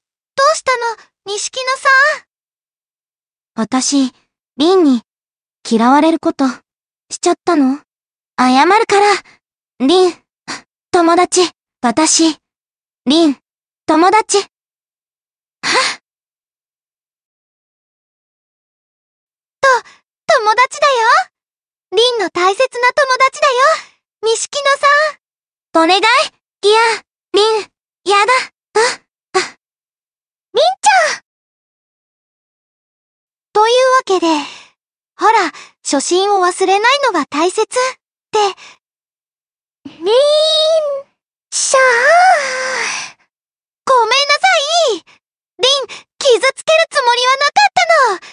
注意：過去のラ！板ｓｓで自分の好きなものを、最近はやりの中華ＡＩツールに読ませてみました。
暗い声色が少ないから悲しんでてもテンション高いな笑
音程の調整はできないんだよ